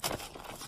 Footsteps from King Brad- Er...I mean Wrath.wav